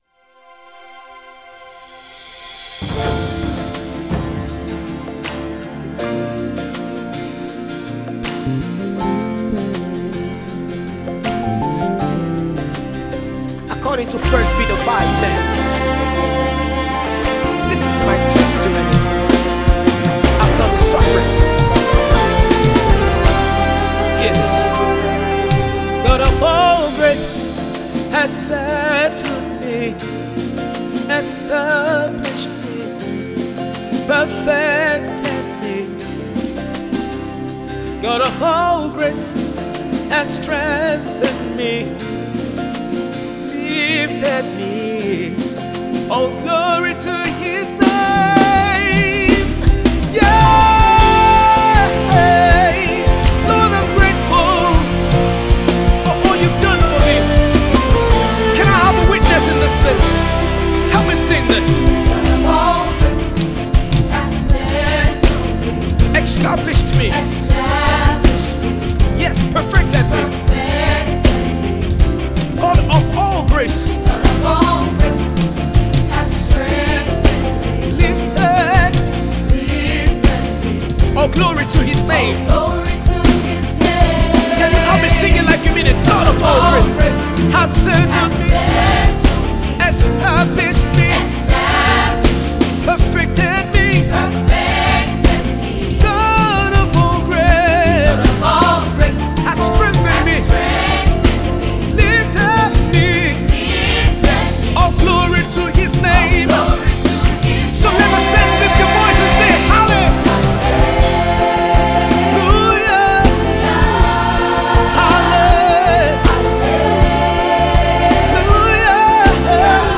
Energetic and Vibrant gospel music minister